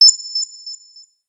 turret_ping.wav